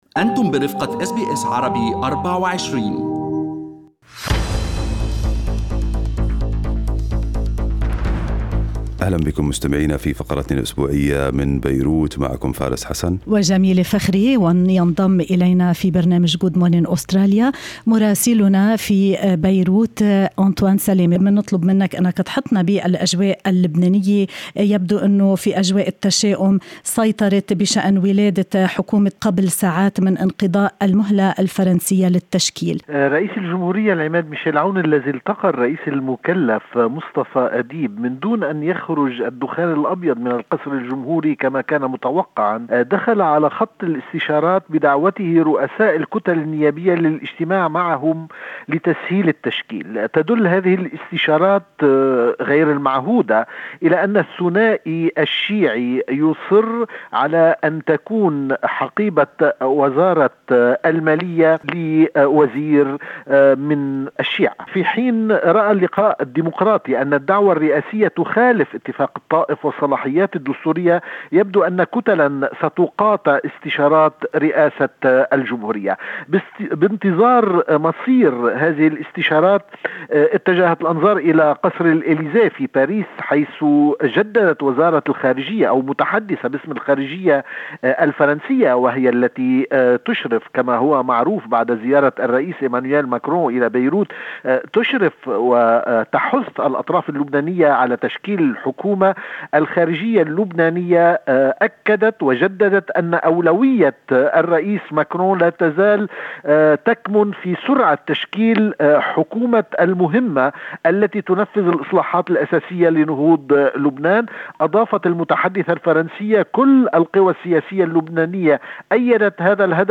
من مراسلينا: أخبار لبنان في أسبوع 15/9/2020